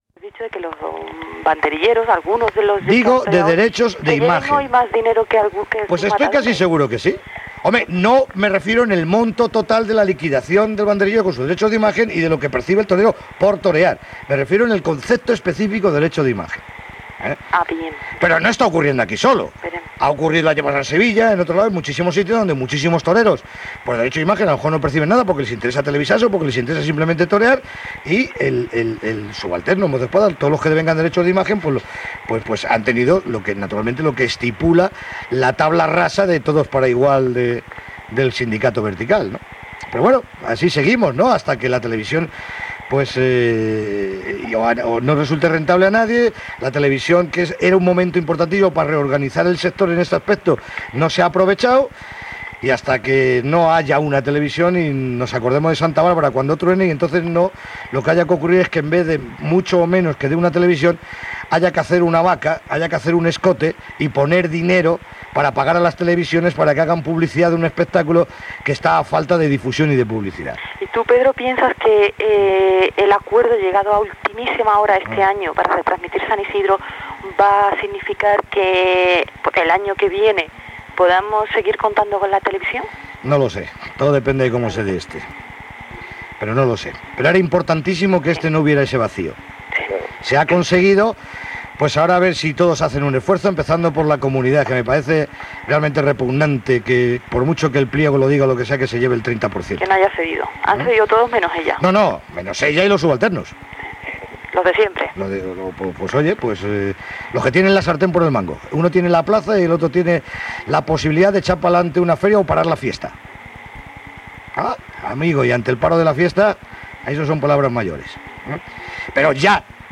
Drets d'imatge dels banderillers, final de la tertúlia taurina.